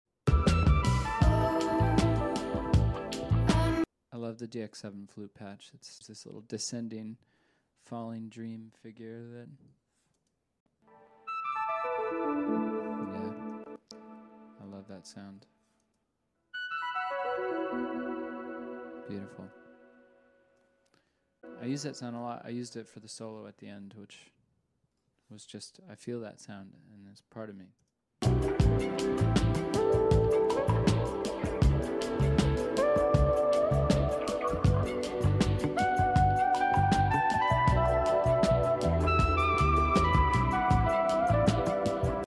Magic dx7 flute patch 🪈🪄🕊 sound effects free download